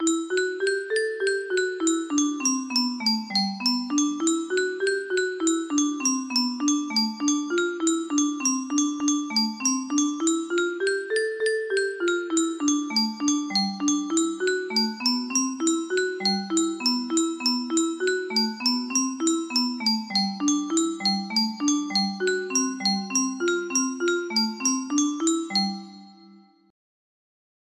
MELODIA BARROCAS music box melody